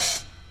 OpenHattingz_Short.wav